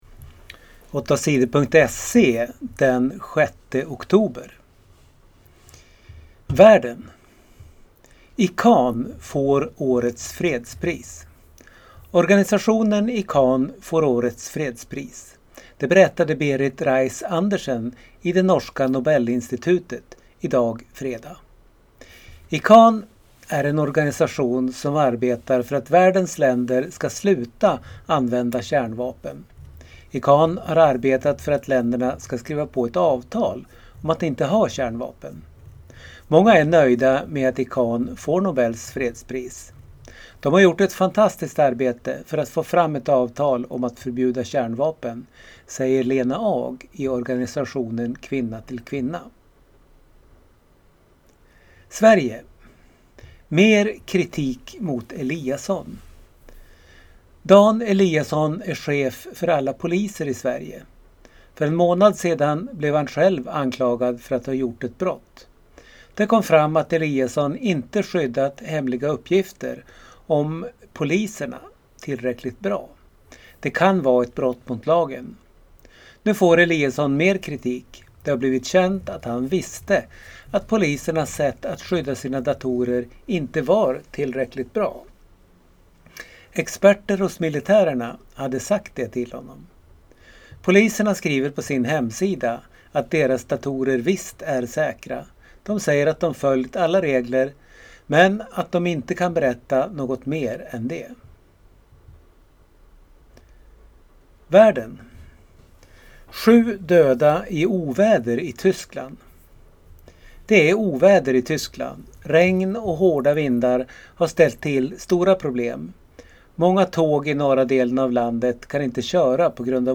Lyssna på nyheter från fredagen den 6 oktober